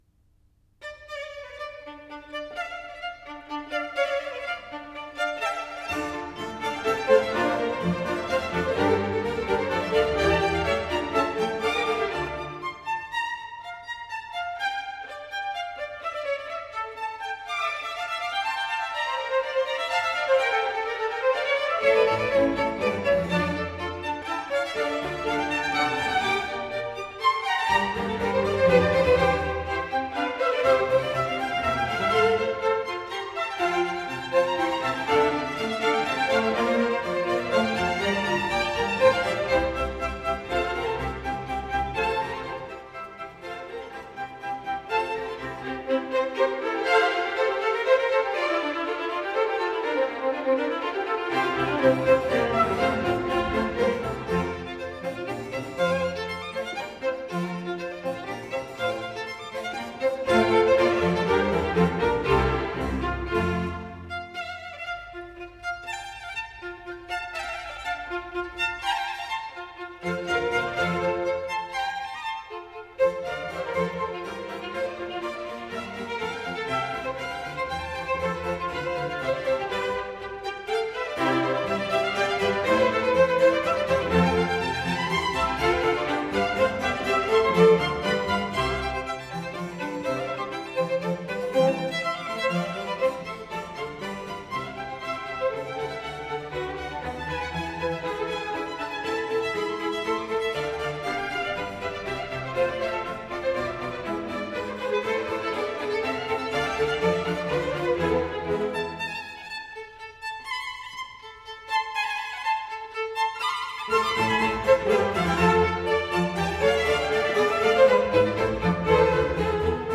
11 - Concerto Grosso Op.6 No.10 in D minor - Allegro